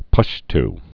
(pŭsht)